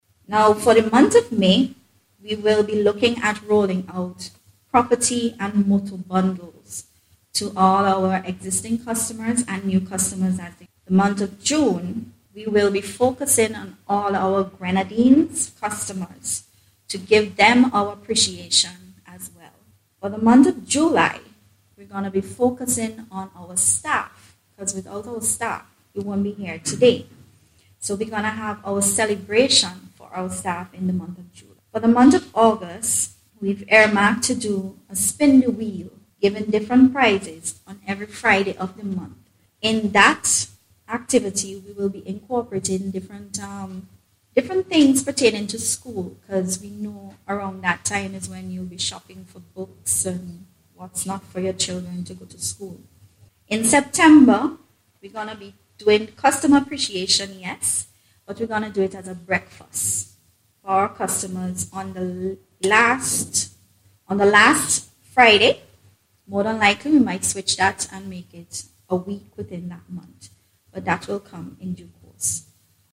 The company hosted a Media Launch last Thursday to signal the start of activities.